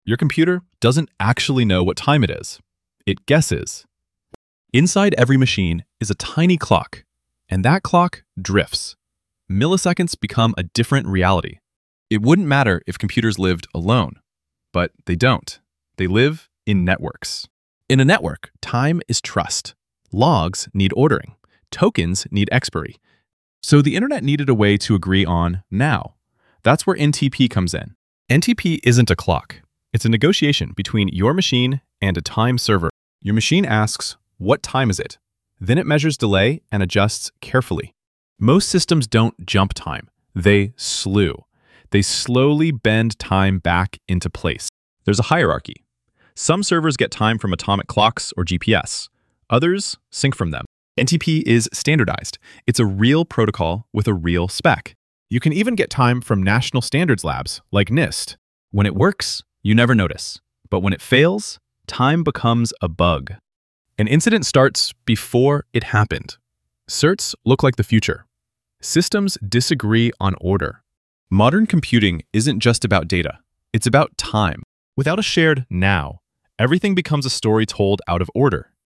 Voiceover-only + script/storyboard.